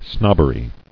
[snob·ber·y]